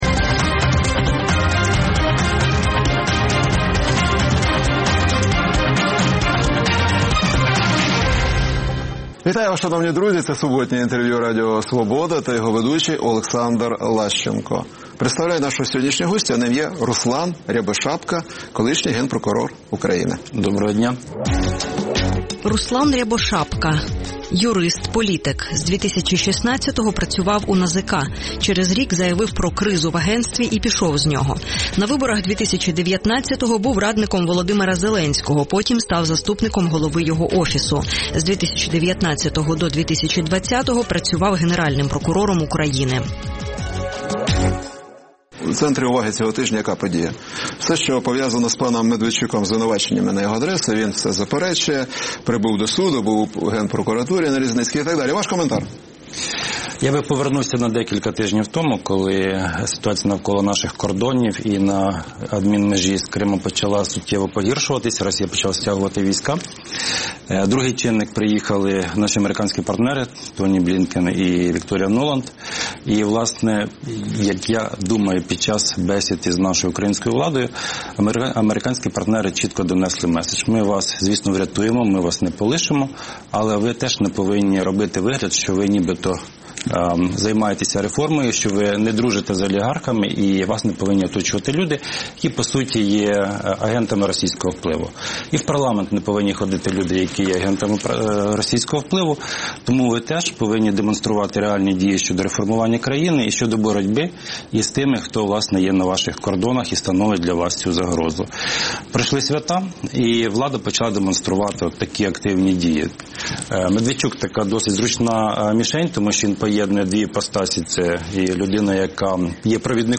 Суботнє інтерв’ю | Руслан Рябошапка, ексгенпрокурор України
Суботнє інтвер’ю - розмова про актуальні проблеми тижня. Гість відповідає, в першу чергу, на запитання друзів Радіо Свобода у Фейсбуці